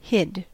Ääntäminen
Ääntäminen US Tuntematon aksentti: IPA : /hɪd/ Haettu sana löytyi näillä lähdekielillä: englanti Hid on sanan hide partisiipin perfekti (vanhahtava).